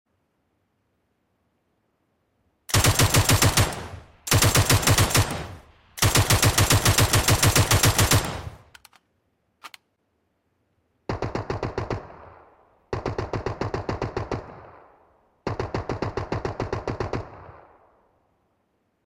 Parte 22 | MK47 IN LONG RANGE SOUND